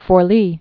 (fôr-lē)